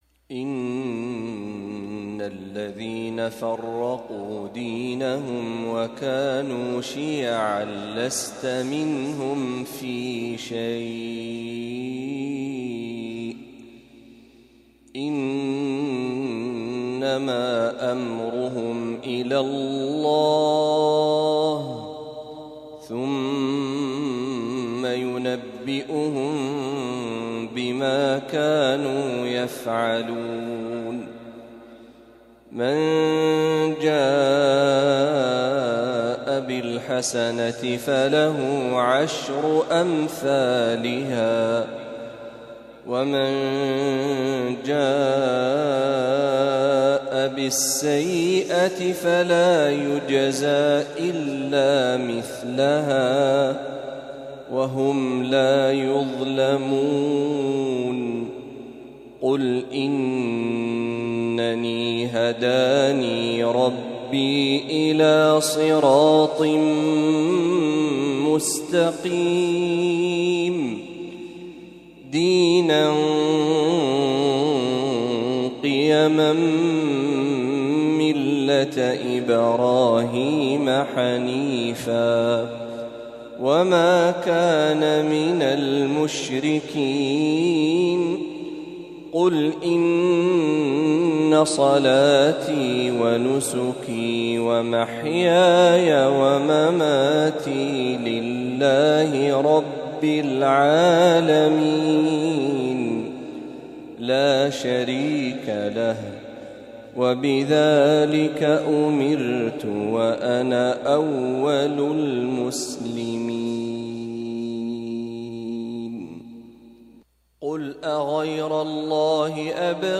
خواتيم سورة الأنعام | فجر الإثنين ٢٧ ربيع الأول ١٤٤٦هـ > 1446هـ > تلاوات الشيخ محمد برهجي > المزيد - تلاوات الحرمين